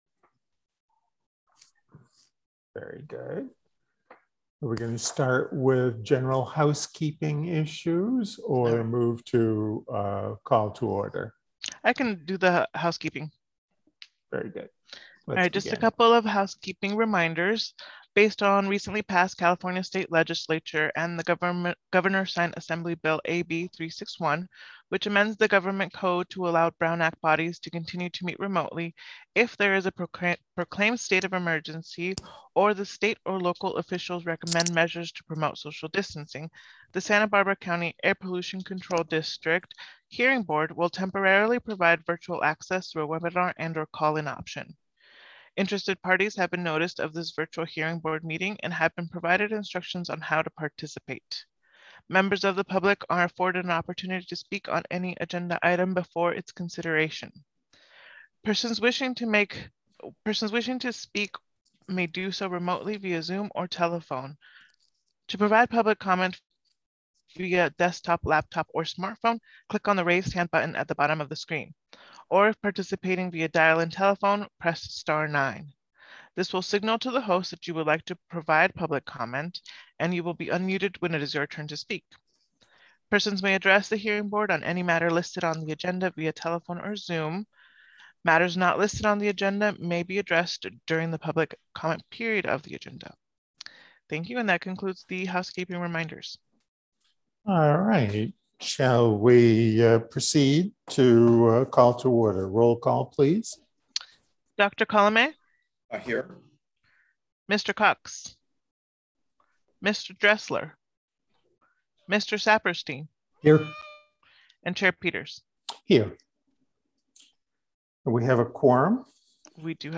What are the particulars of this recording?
December 20, 2021 – Special Meeting